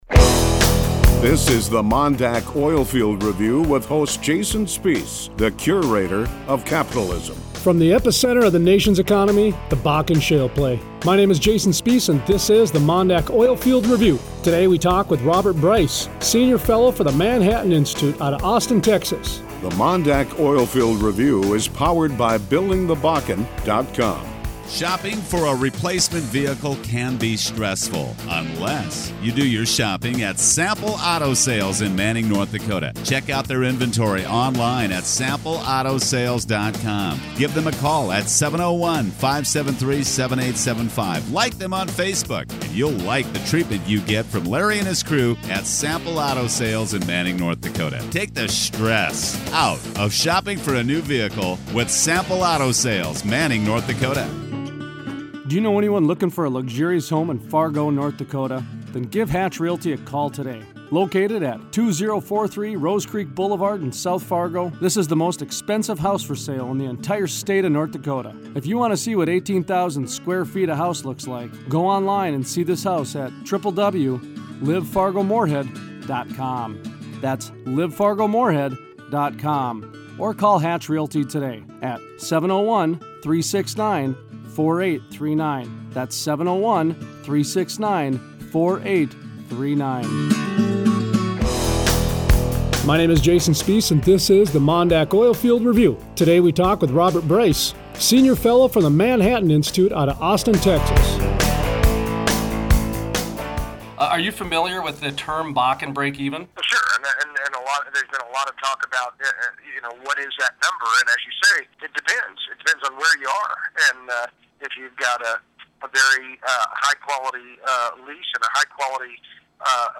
Friday 7/24 Interview